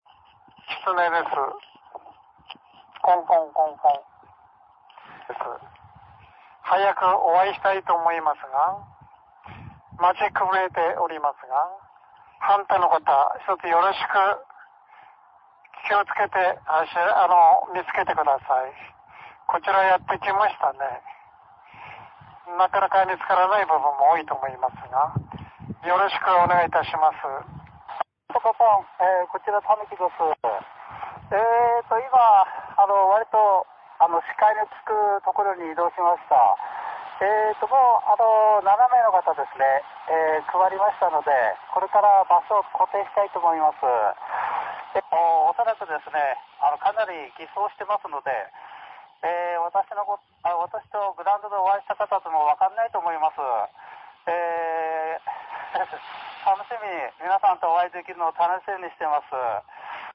JARL渡島檜山支部のフォックスハンティグ大会が、見晴らし公園で開かれました。